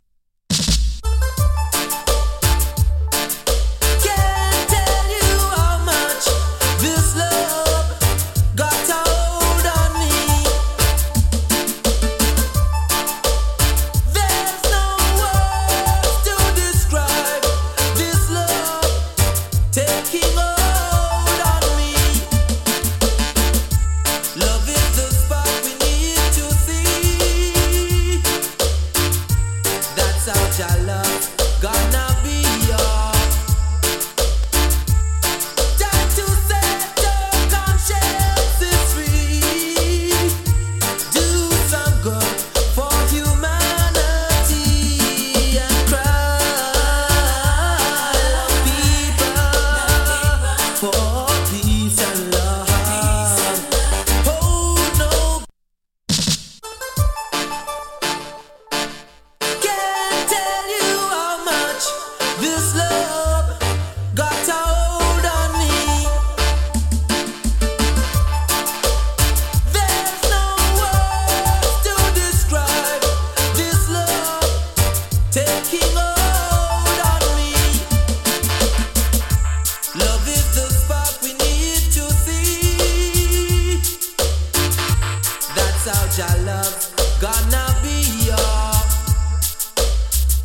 DANCE HALL 90'S
A：VG+ / B：VG+ ＊スリキズ少々有り。チリ、パチノイズわずかに有り。
KILLER DIGITAL ROOTS & DUB ＋ ACCAPELLA.